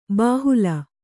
♪ bāhula